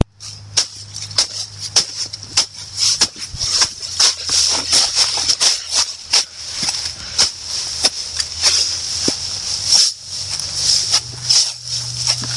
环境 " 草地上的沙沙声
描述：草沙沙作响。用变焦记录器记录沙沙叶，并在收割机中编辑它们。
Tag: 风能 沙沙作响 树叶